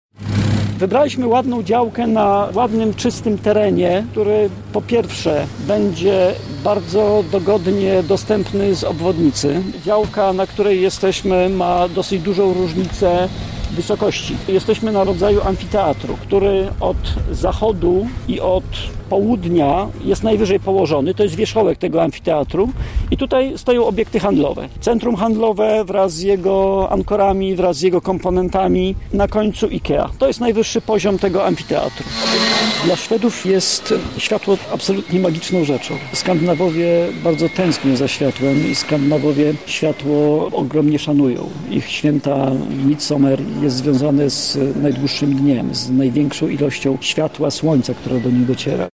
W czwartek, 17 sierpnia, na placu budowy były nasze reporterki.